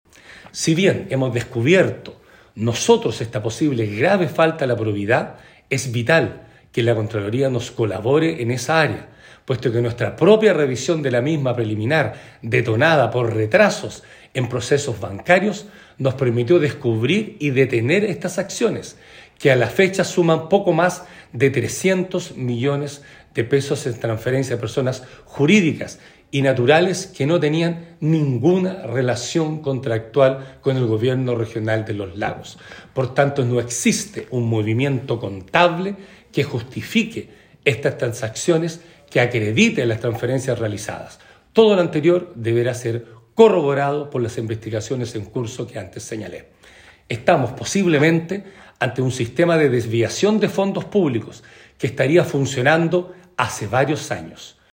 La máxima autoridad regional enfatizó en esperan trabajar en conjunto a la Contraloría General de la República, ya que estas transacciones no tienen ningún tipo de relación contractual con el Gobierno Regional, desvíos que se vendrían realizando de manera sistematizada desde el año 2019.
16-agosto-23-patricio-vallespin-desviacion.mp3